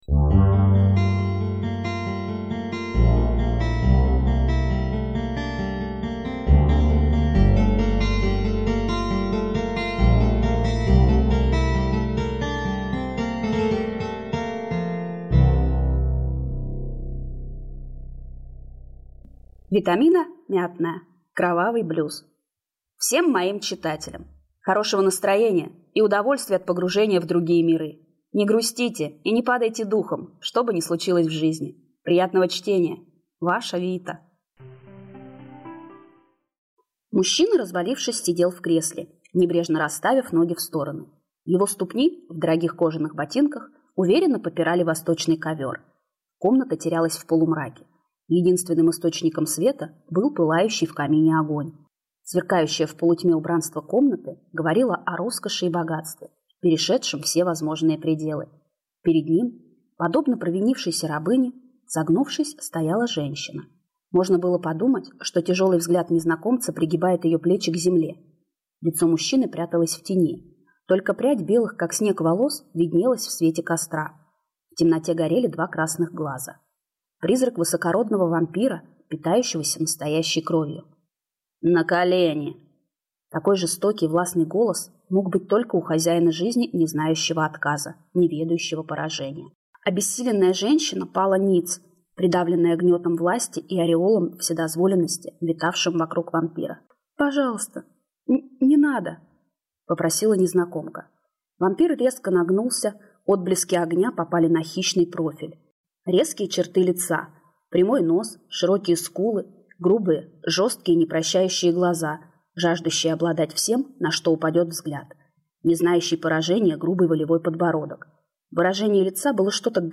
Аудиокнига Кровавый блюз | Библиотека аудиокниг